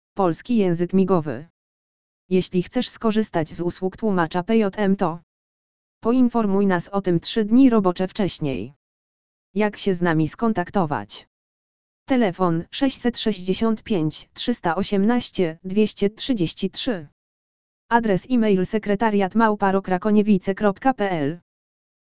LEKTOR AUDIO POLSKI JĘZYK MIGOWY
lektor_audio_polski_jezyk_migowy.mp3